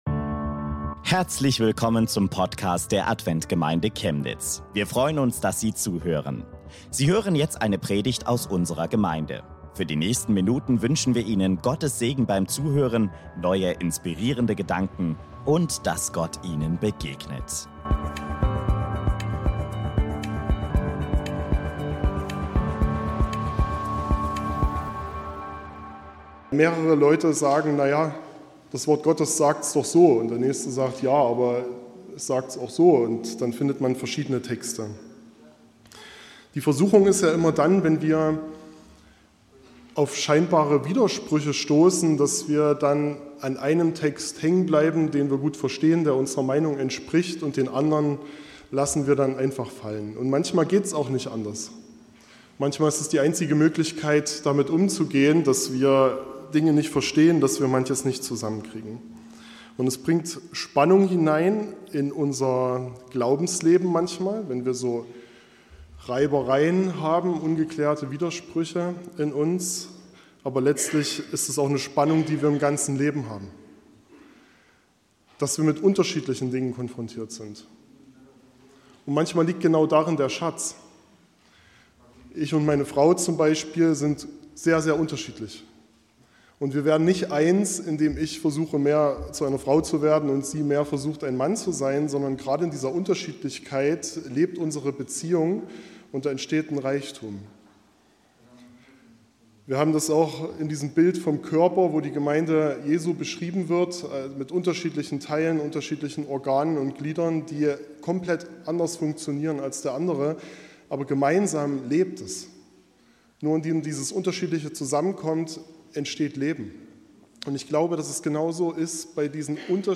Geistliches Leben im Spannungsfeld gegensätzlicher biblischer Aussagen ~ Adventgemeinde Chemnitz - Predigten Podcast